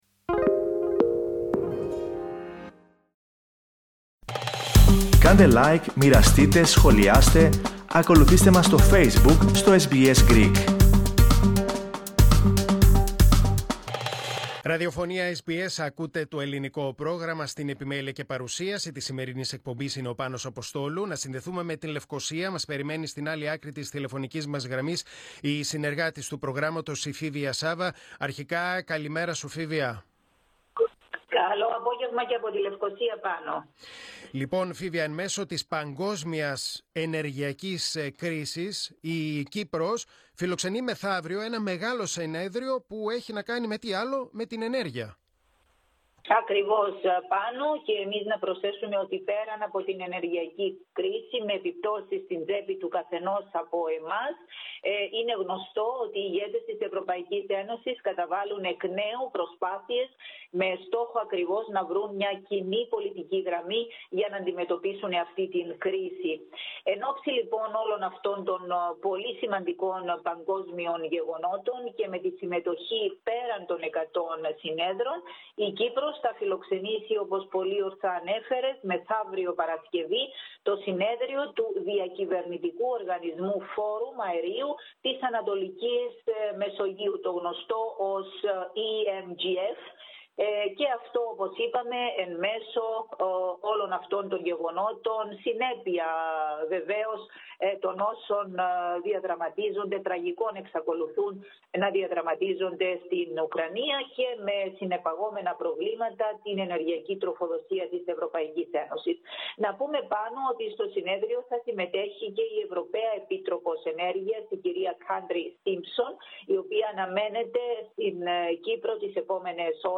Ακούστε, όμως, ολόκληρη την ανταπόκριση από την Κύπρο.